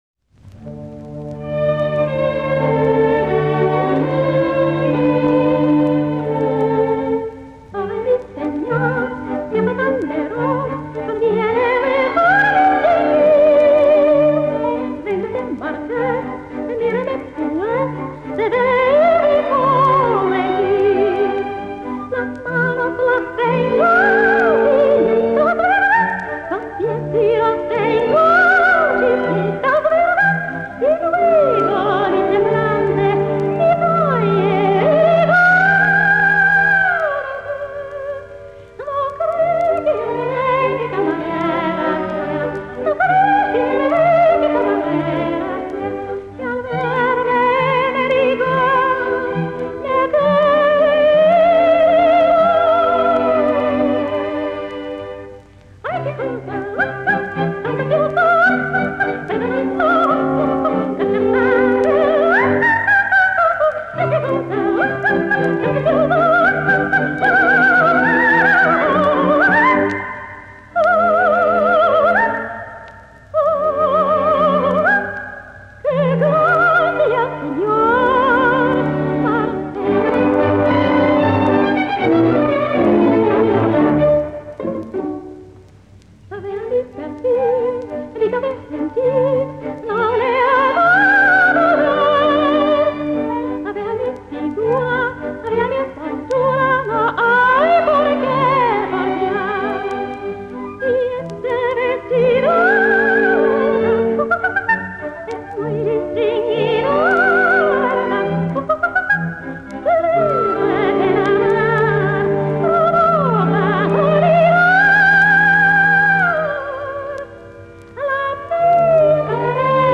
И.Штраус -Летучая мышь - Вальс - М.Корьюс